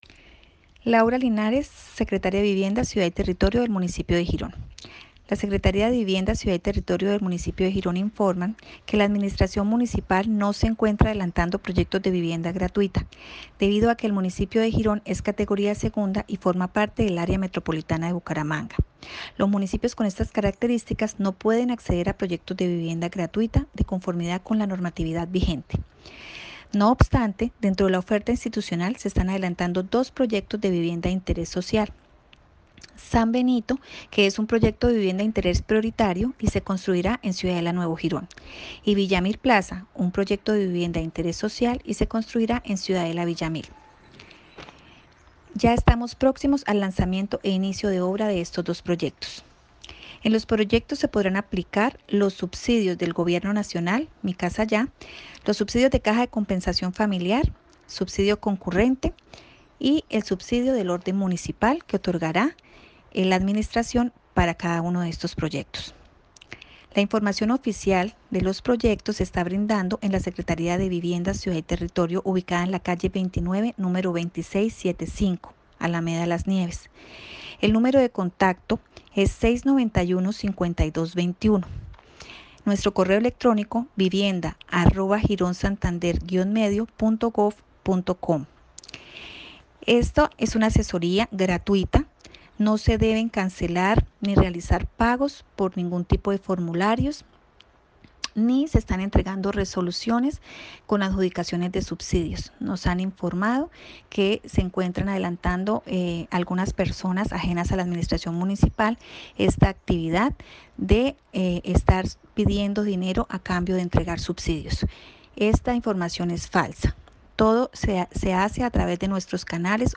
Laura Linares, Secretaria de Vivienda.mp3